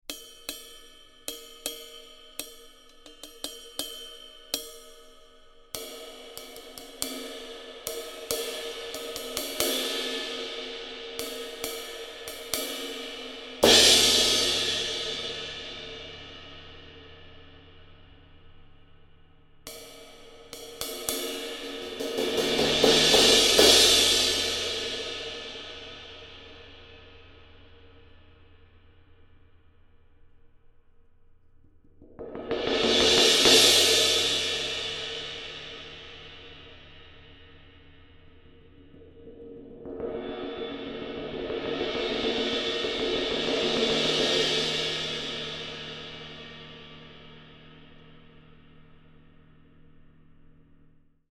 Combining stripes of alternating lathing and raw (unlathed) top surface and a fully lathed bottom, these cymbals combine the sounds of our popular Classic, Studio and Custom series all into one.
Anthem 20″ Crash Cymbal (approximate weight 1655 grams):